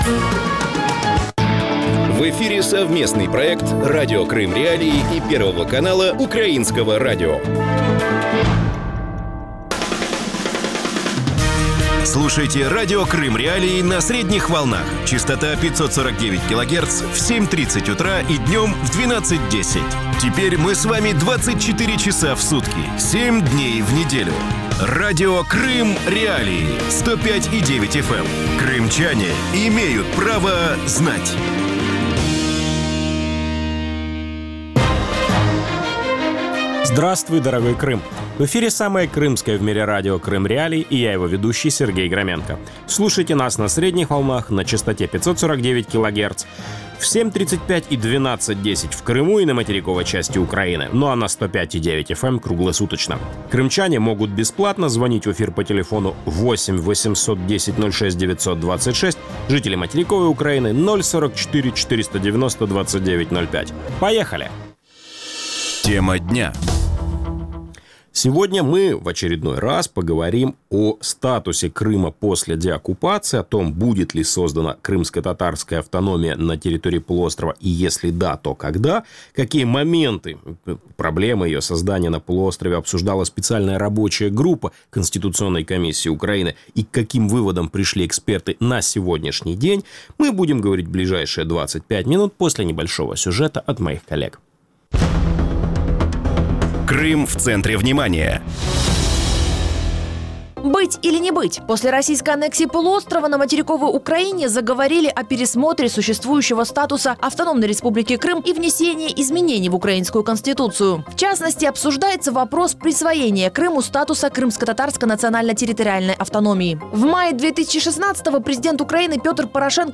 Каковы аргументы сторонников и противников крымскотатарской автономии? Гости эфира